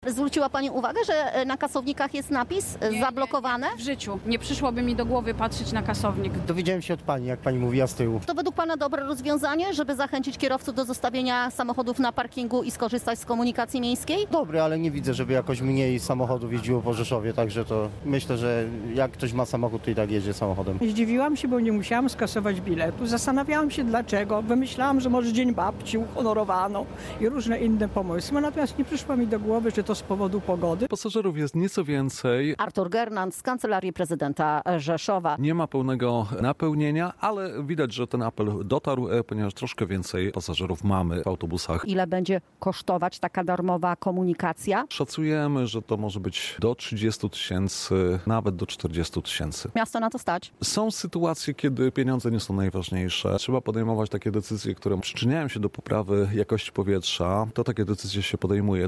Dziś w Rzeszowie darmowa komunikacja miejska z powodu smogu • Relacje reporterskie • Polskie Radio Rzeszów